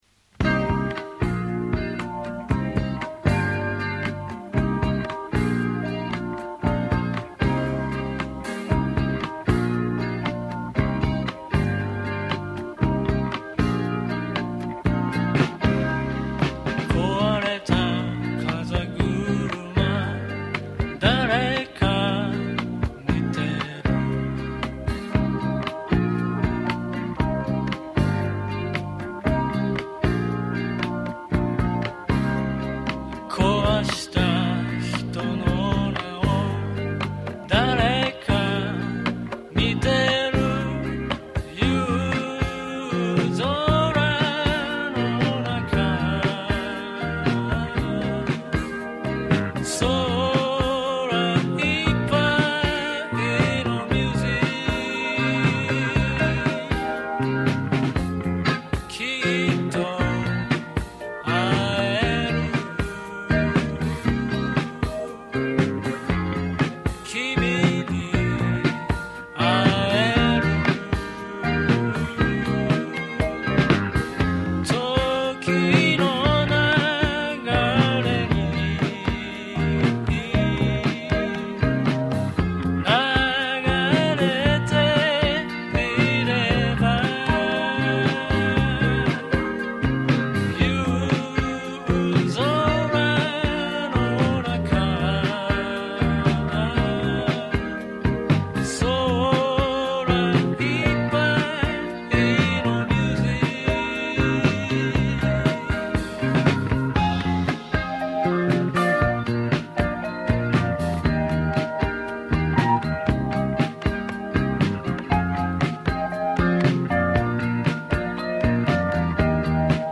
Guitar
Organ